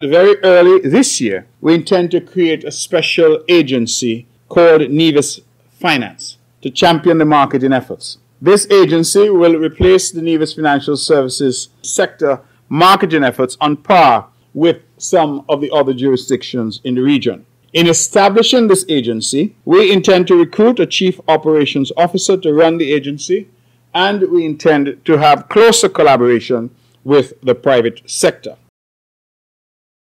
Nevis’ Premier and Minister of Finance, the Hon. Mark Brantley, as he spoke late last month at his press conference.